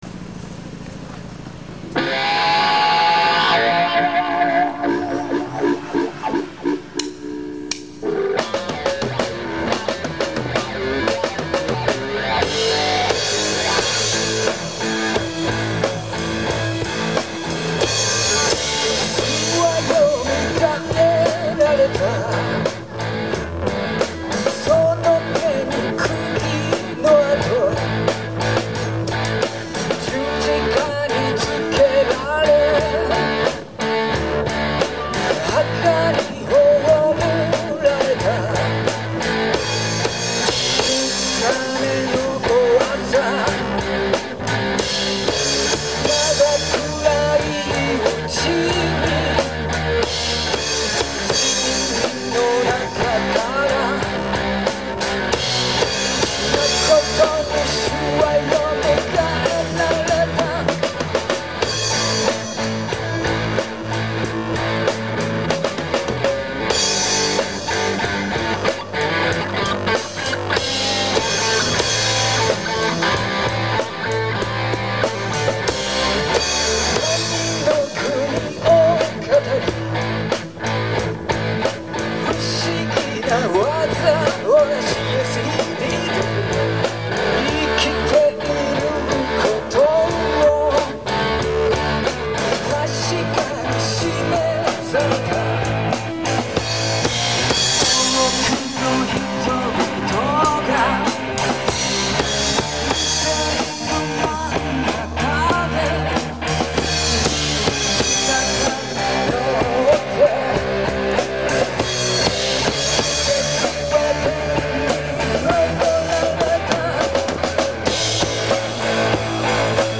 ウルトラへビィ＆ブルージー
2007年の「街中コンサート」という一般のライブで録音した。
オリジナルはZZTOPみたいな感じにしようと多重録音したハードロックだったけど、バンドではリズムを変えて、へビィなシャッフルにした。